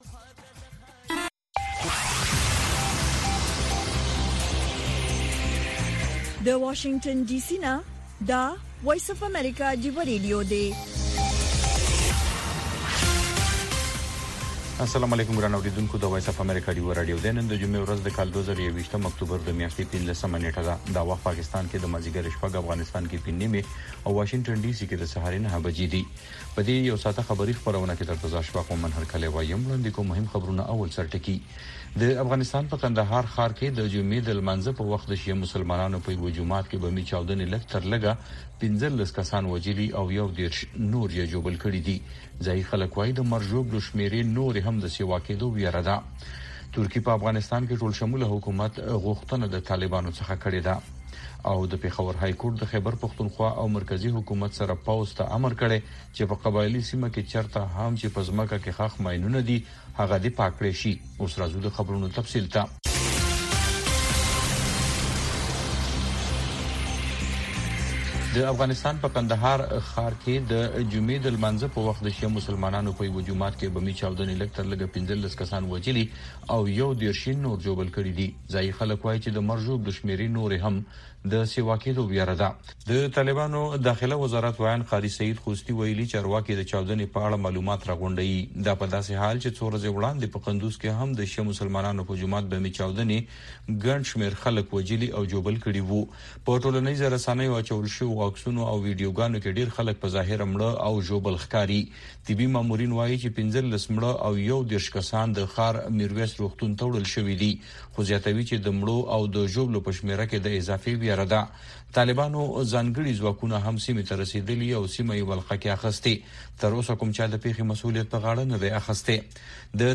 د وی او اې ډيوه راډيو ماښامنۍ خبرونه چالان کړئ اؤ د ورځې د مهمو تازه خبرونو سرليکونه واورئ.